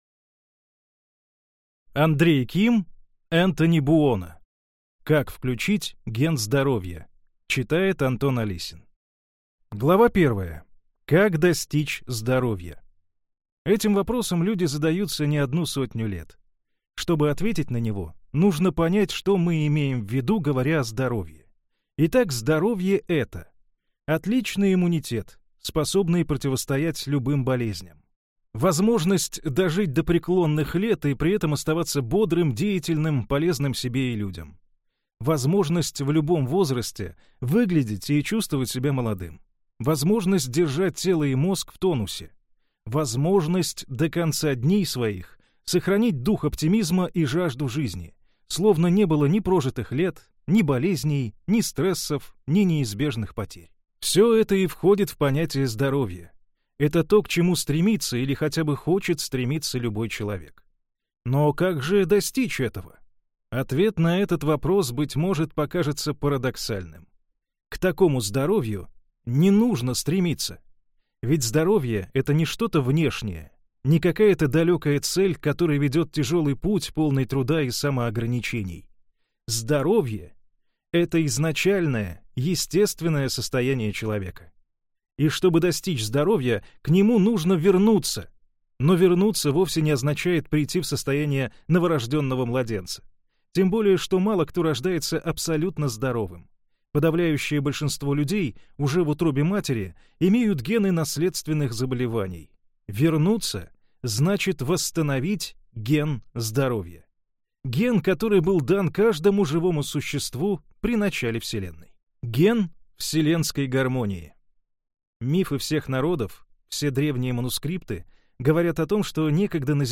Аудиокнига Как включить ген здоровья. Программируем организм на уровне ДНК | Библиотека аудиокниг